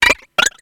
Cri de Vivaldaim dans Pokémon X et Y.